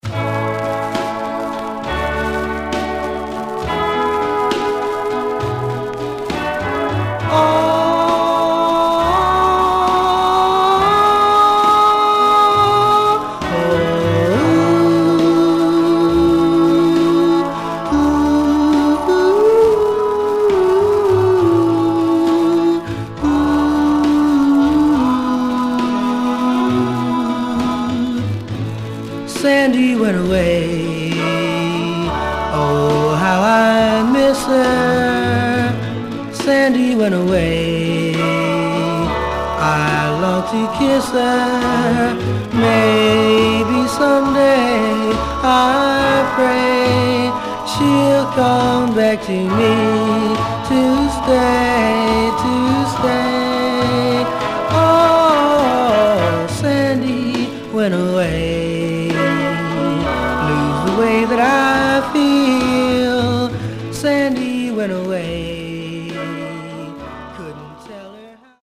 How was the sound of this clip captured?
Stereo/mono Mono